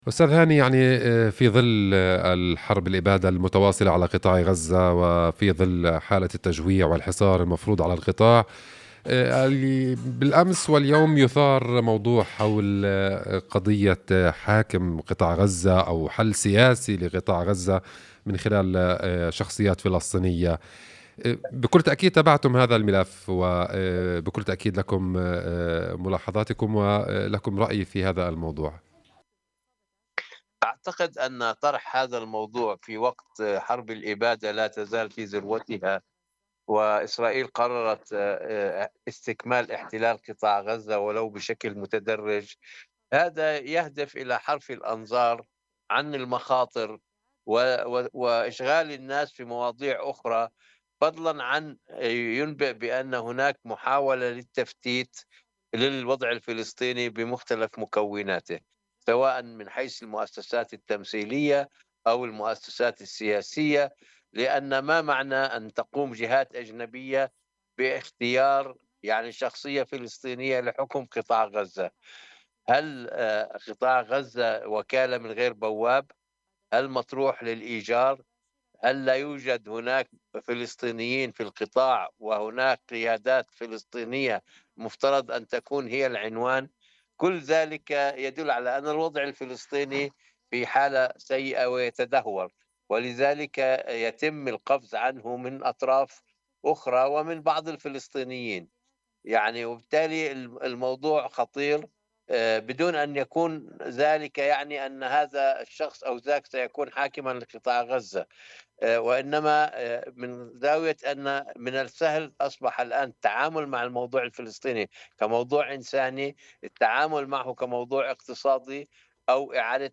الكاتب والمحلل السياسي